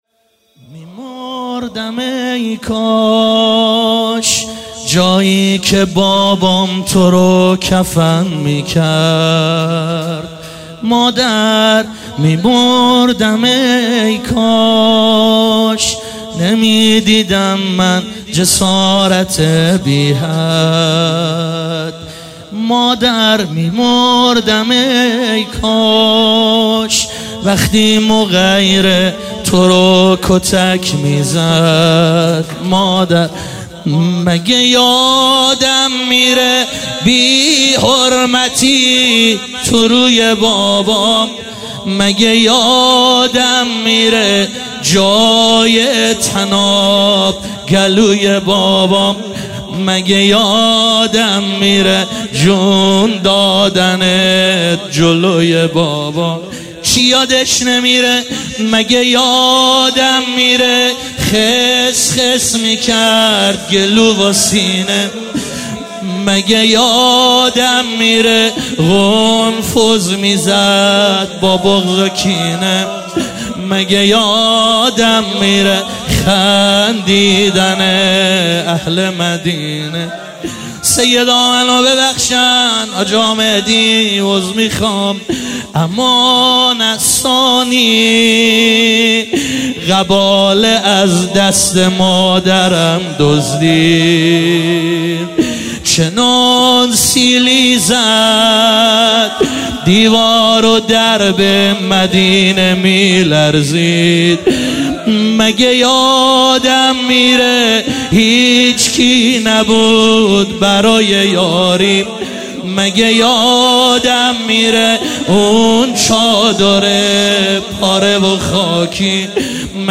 برگزار کننده هیئت حسین جان علیه السلام گرگان
شور